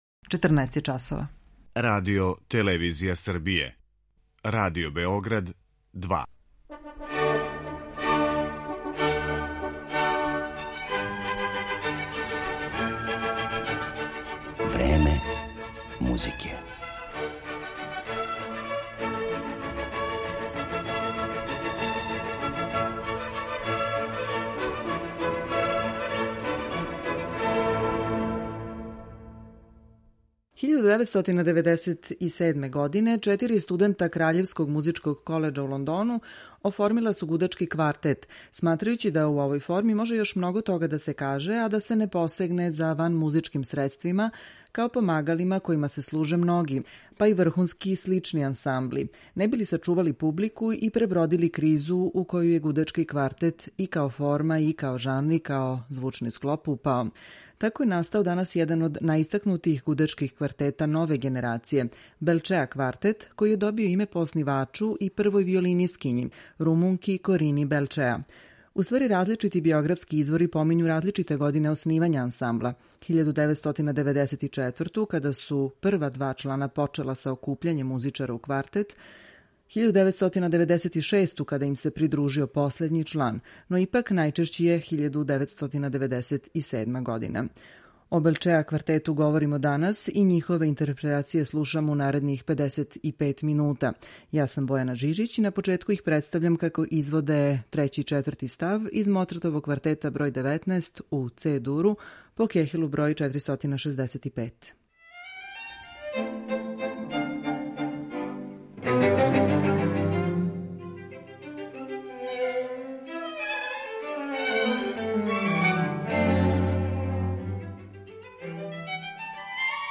гудачком квартету